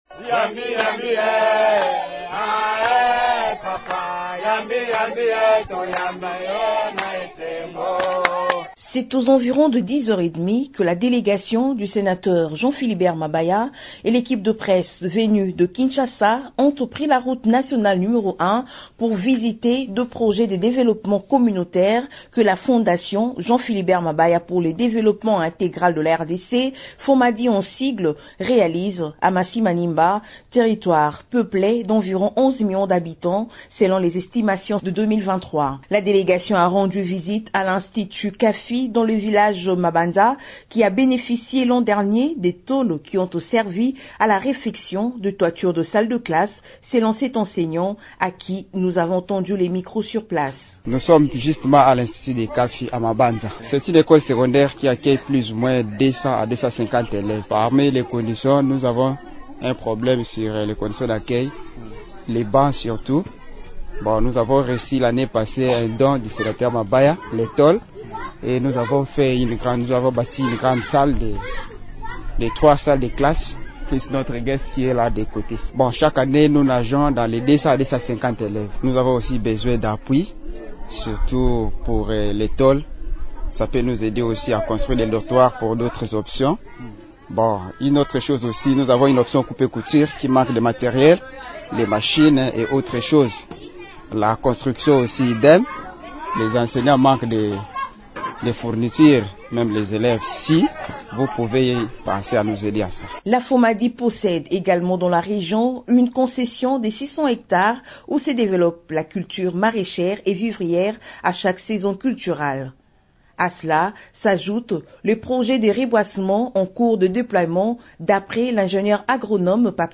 grand reportage